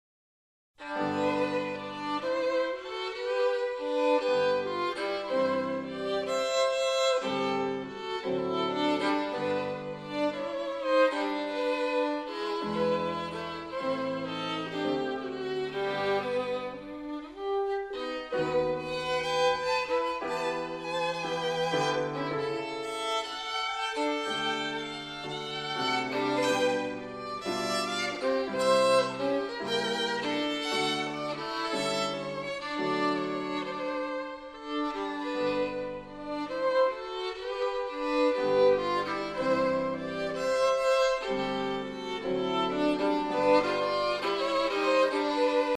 für Violine und Klavier / for violin and piano.